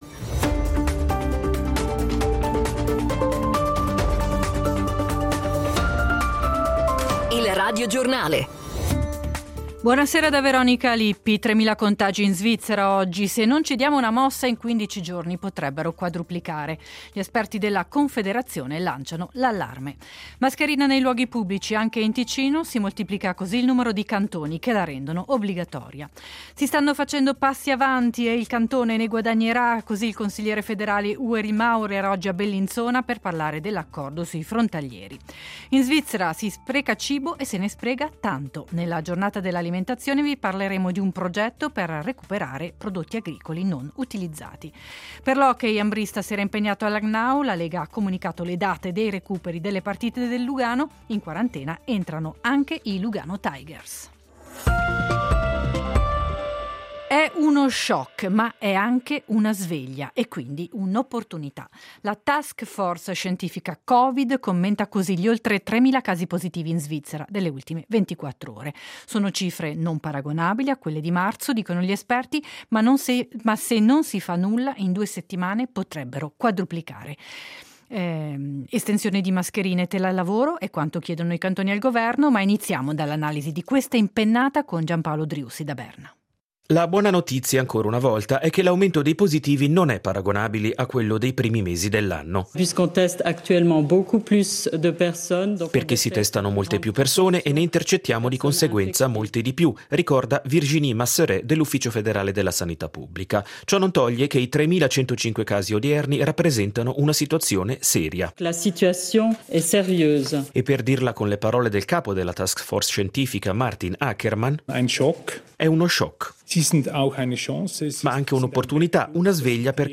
Notizie